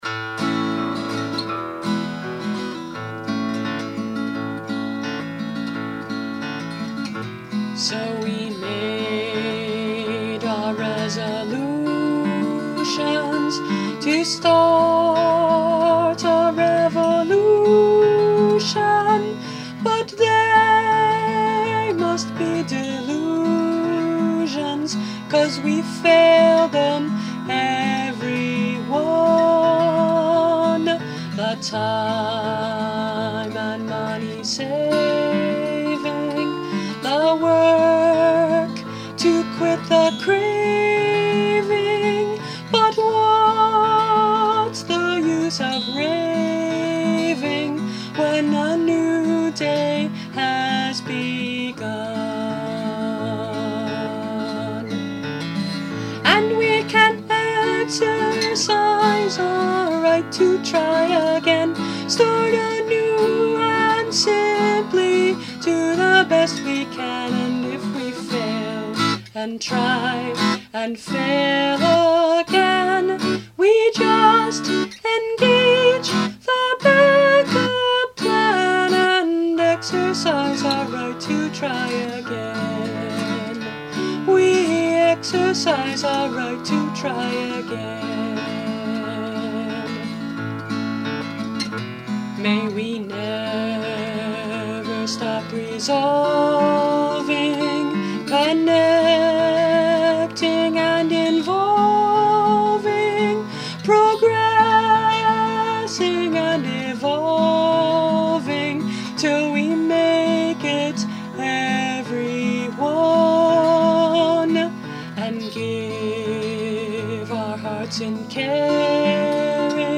Instrument: Tempo – Seagull Excursion Folk Acoustic Guitar